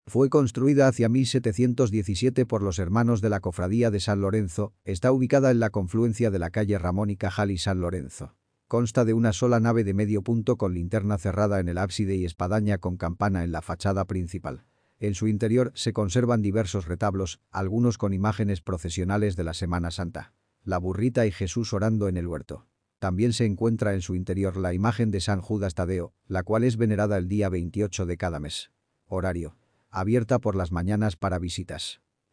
Audioguía